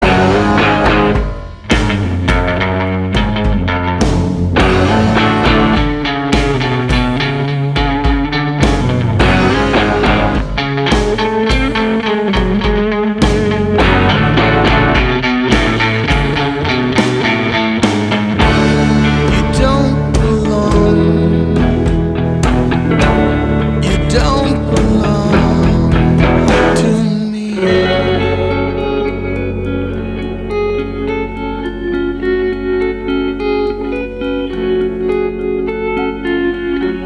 • Качество: 320, Stereo
гитара
инструментальные
латинские
blues Rock
chicano rock
latin rock
бас-гитара